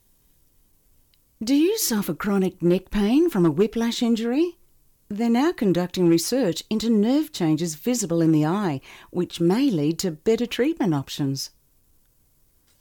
Professionally recorded phone messages
Male and female voice artists have clear enunciation and exceptional voice skills to convey your message in a friendly, pleasant manner.
Female voice - Style 1
Female-voice-artist-style-2.mp3